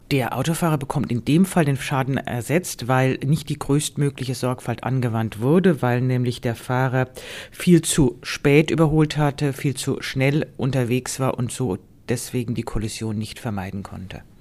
O-Ton: Auch getarnte Fahrzeuge dürfen Sonderrechte nutzen – müssen aber sorgsam sein